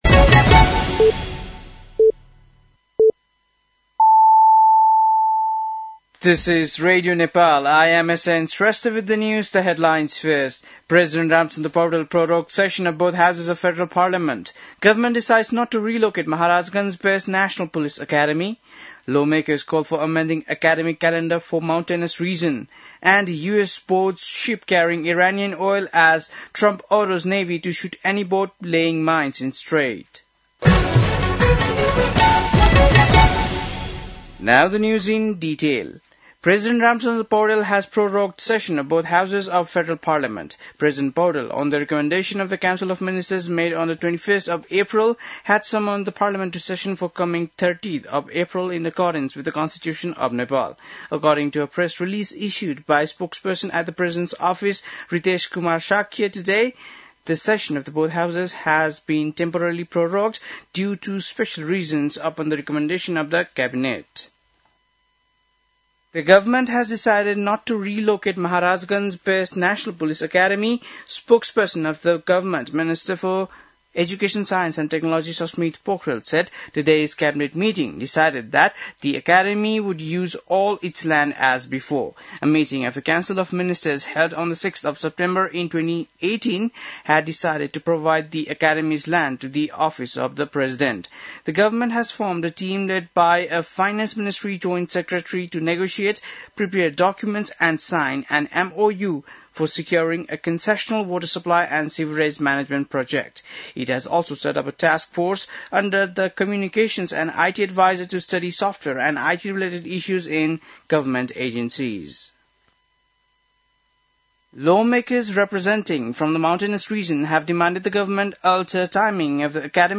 बेलुकी ८ बजेको अङ्ग्रेजी समाचार : १० वैशाख , २०८३
8-pm-english-news-1-10.mp3